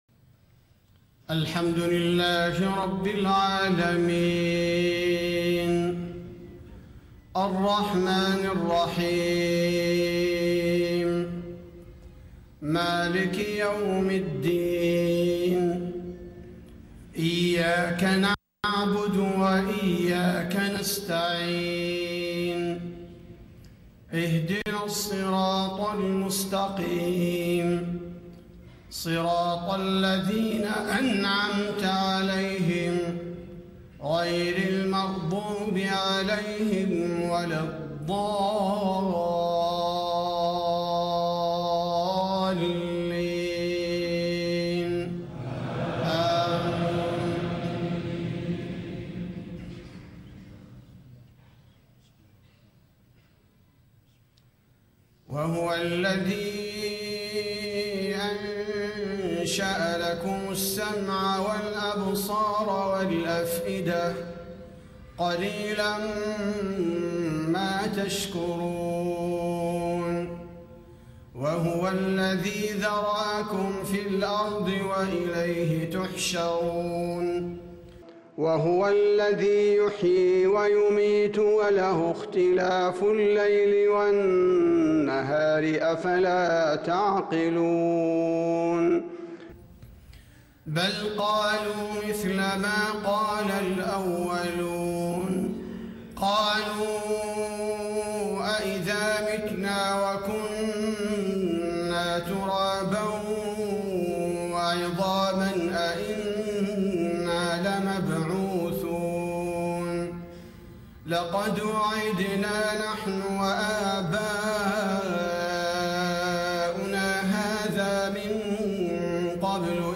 صلاة العشاء 3-6-1435 ما تيسر من سورة المؤمنون > 1435 🕌 > الفروض - تلاوات الحرمين